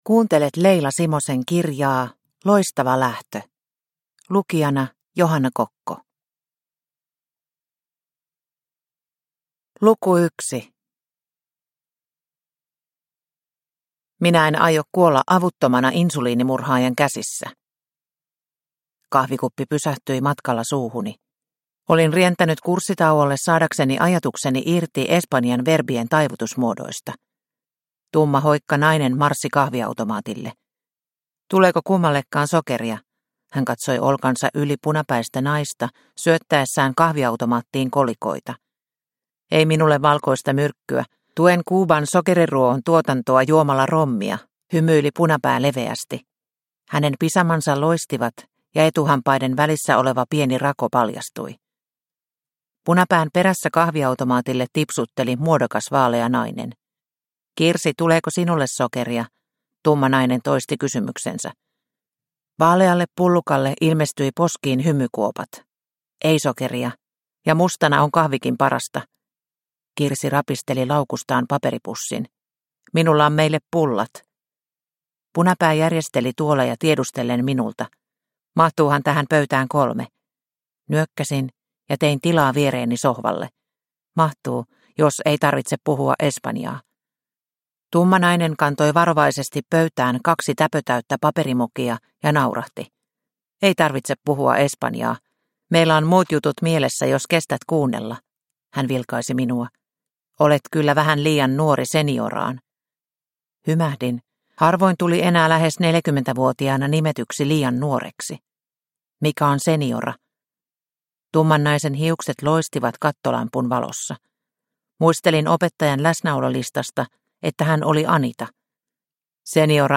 Loistava lähtö – Ljudbok – Laddas ner